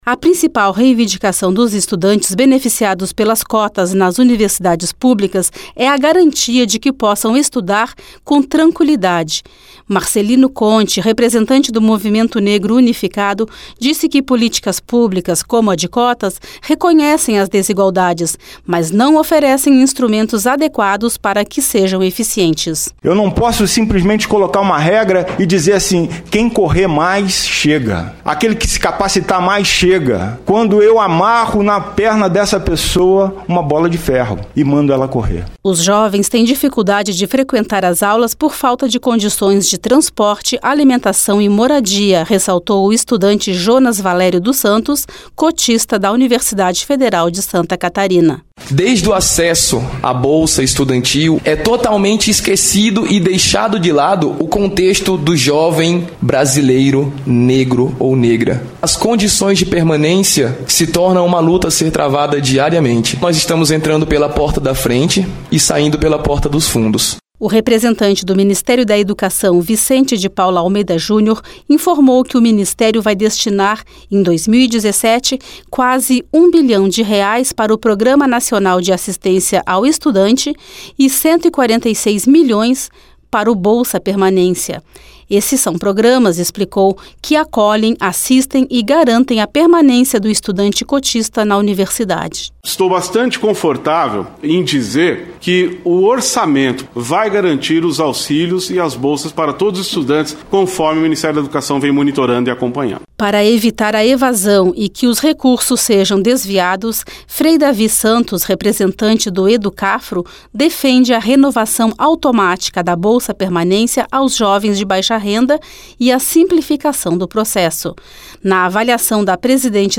Detalhes com a repórter